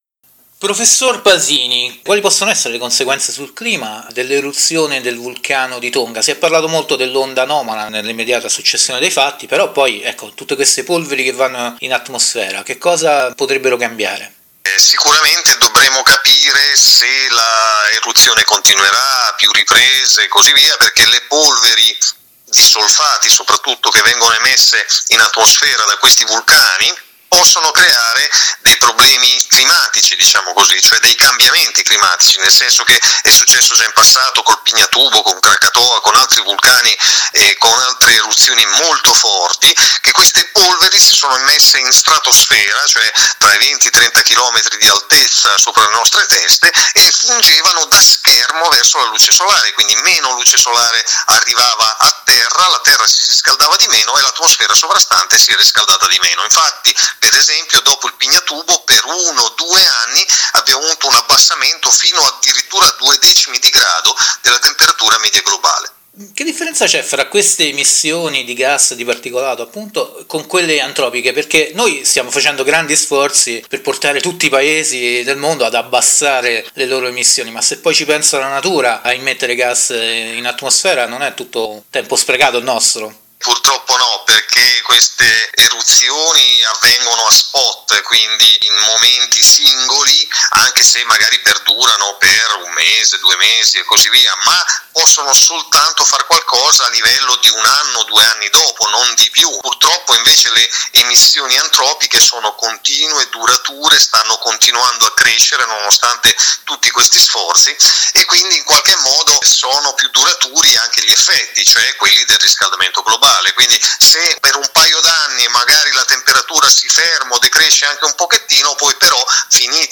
Cambiamento Climatico Ecosistema Interviste